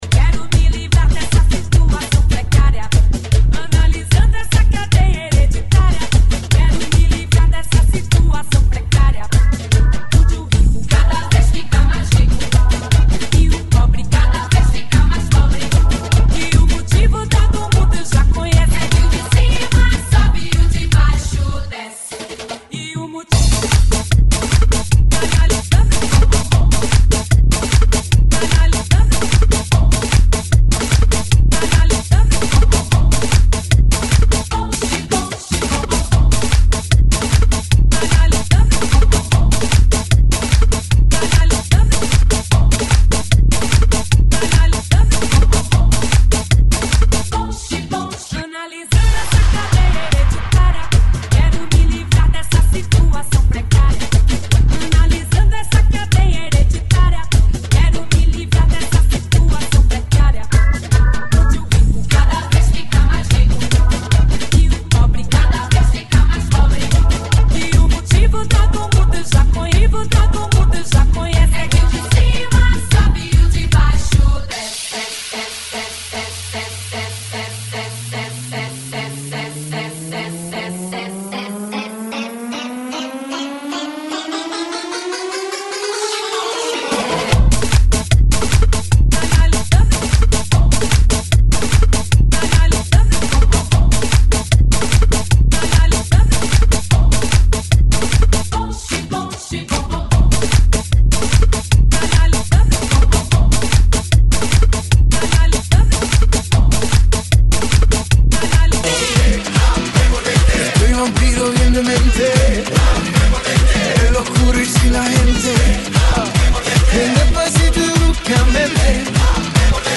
GENERO: LATINO – REMIX